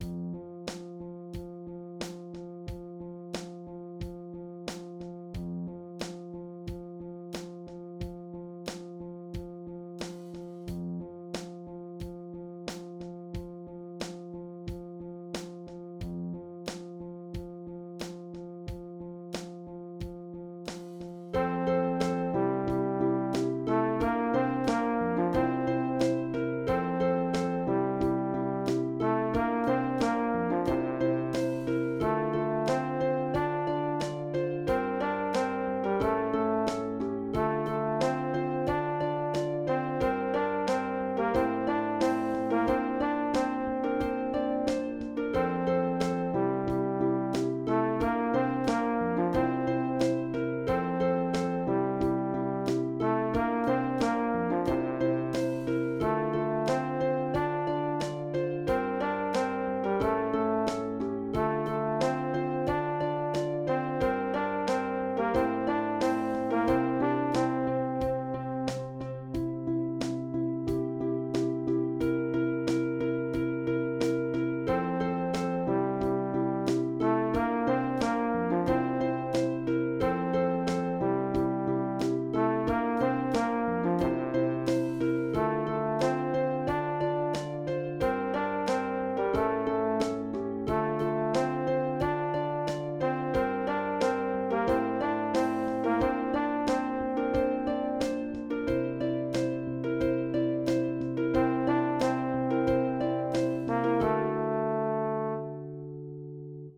Voicing/Instrumentation: Choir Unison
Easter Piano